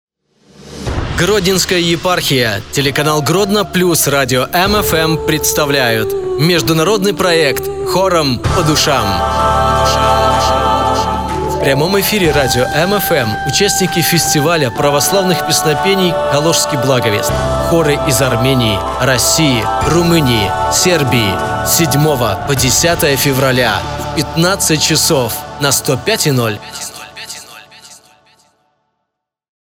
"Хором по душам" - анонс)